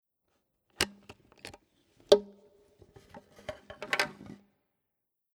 Instruments-Tools-Motors
Garden set Hyvälysti watering can remove
25647_Gartenset_Hyvaelysti_Giesskanne_herausnehmen.mp3